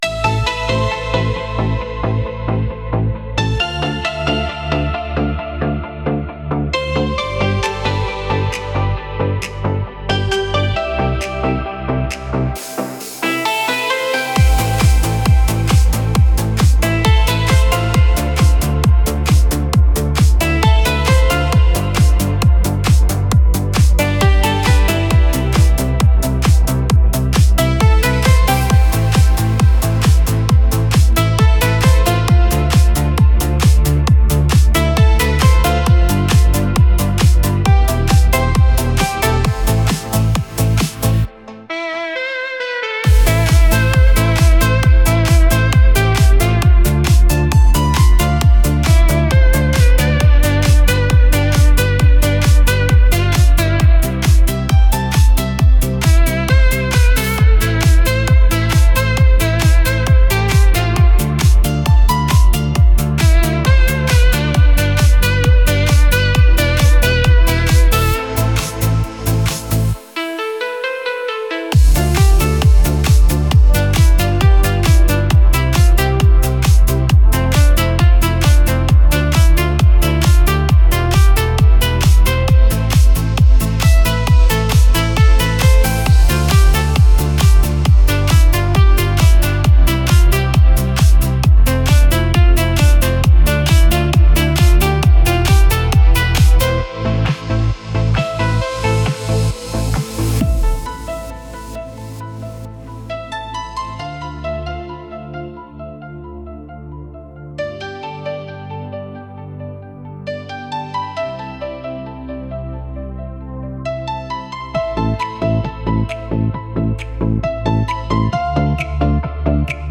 2026·3:06·13 plays·0 fires·instrumental·